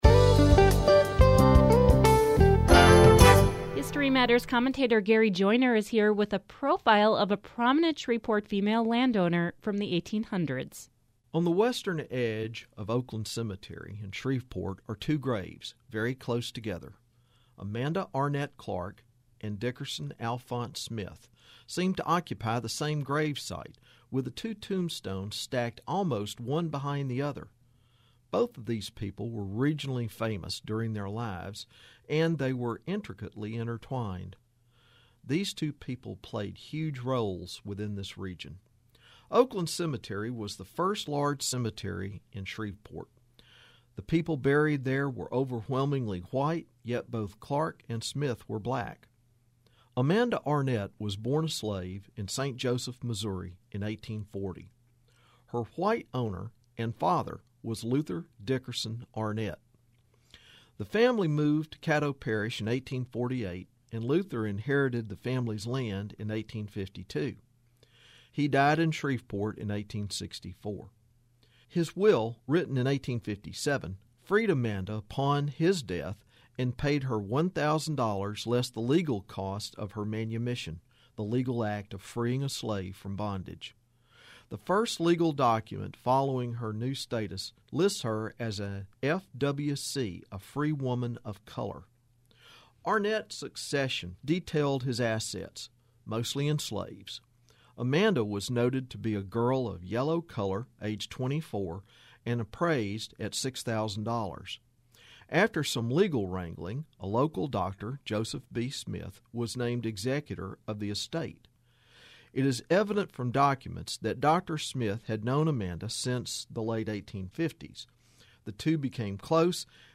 History Matters #413 - Amanda Clark commentary